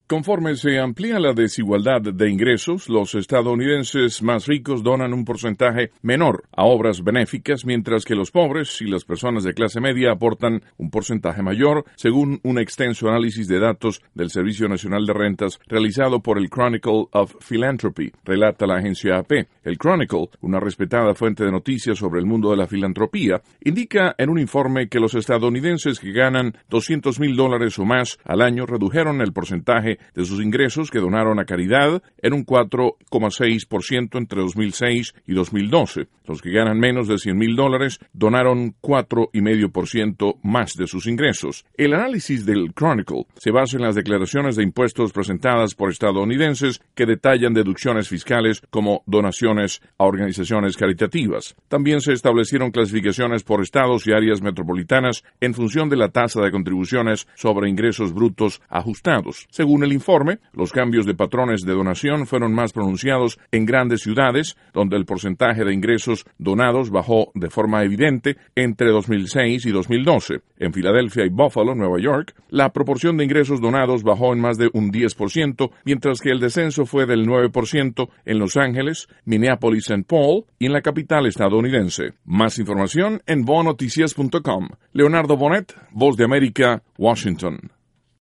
desde la Voz de América, en Washington.